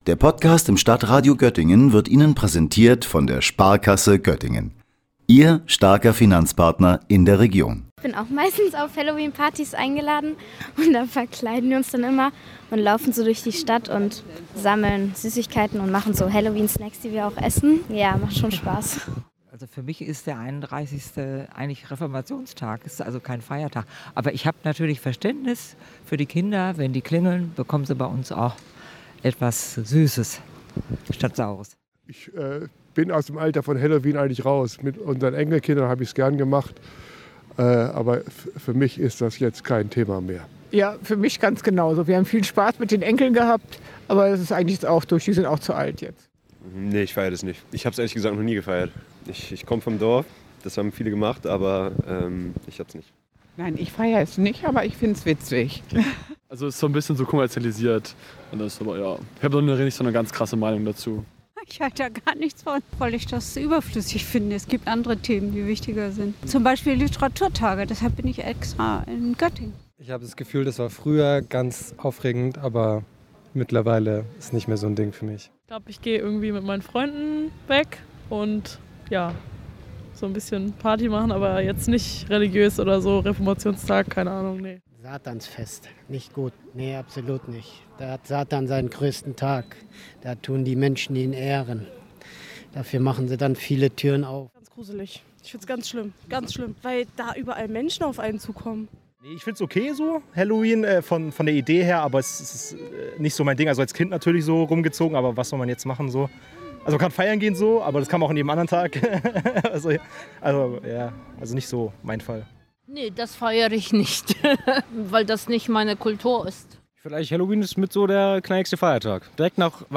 Sendung: Umfragen Redaktion